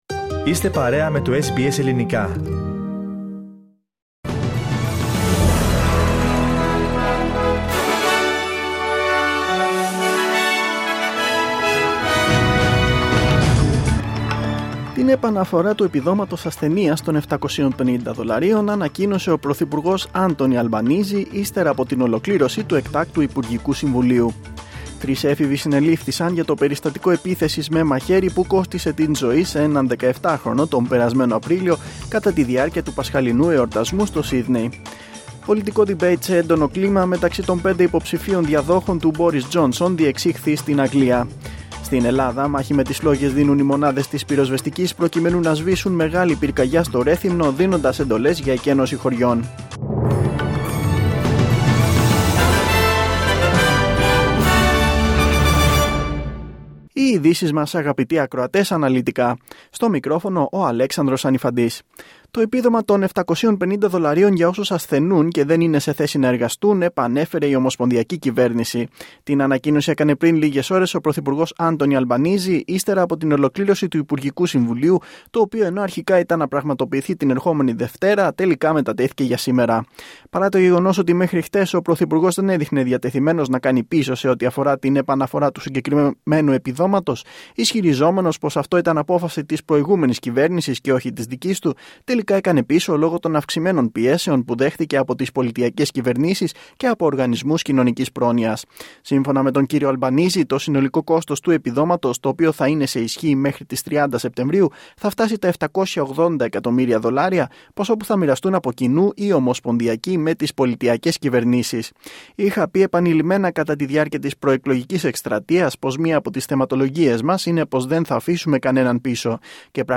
Δελτίο Ειδήσεων Σάββατο 16.7.2022